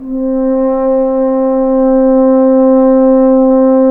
Index of /90_sSampleCDs/Roland L-CDX-03 Disk 2/BRS_French Horn/BRS_F.Horn 3 pp